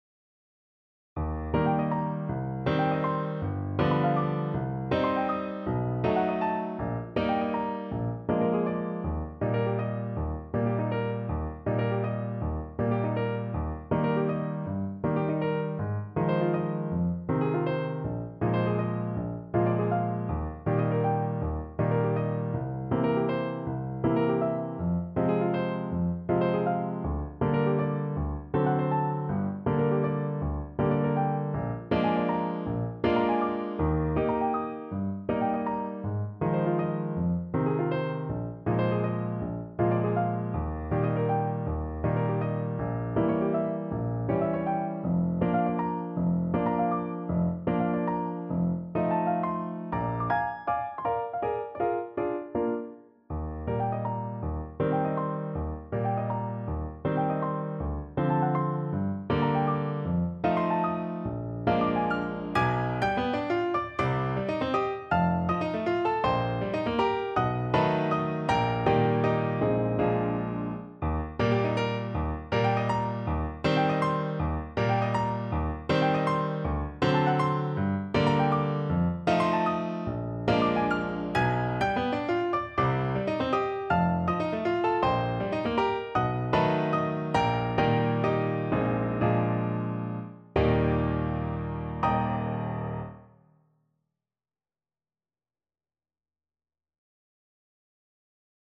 6/8 (View more 6/8 Music)
Classical (View more Classical French Horn Music)